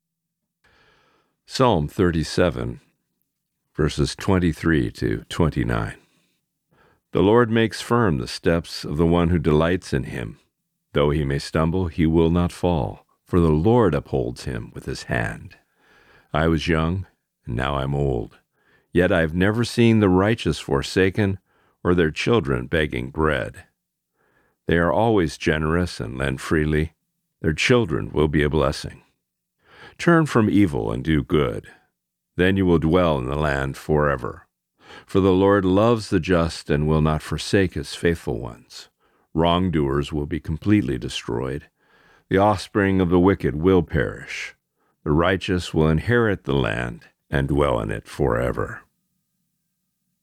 Reading: Psalm 37:23-29